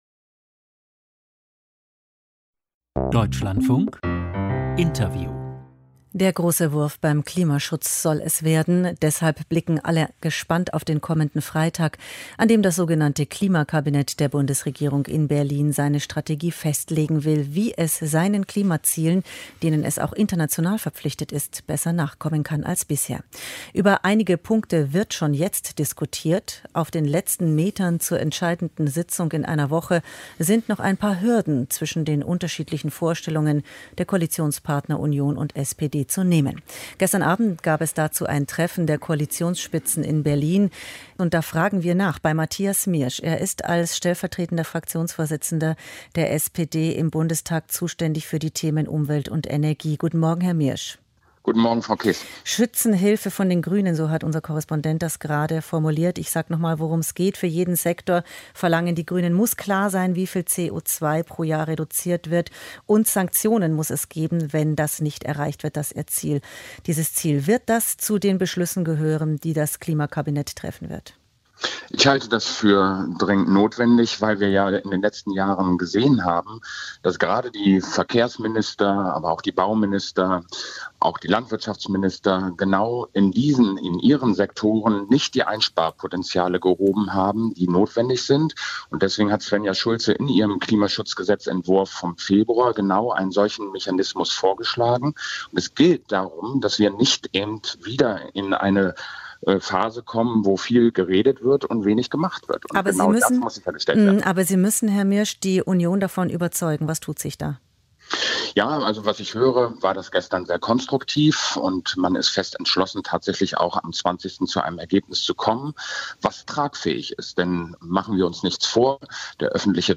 Deutschlandfunk-Interview mit Matthias Miersch , SPD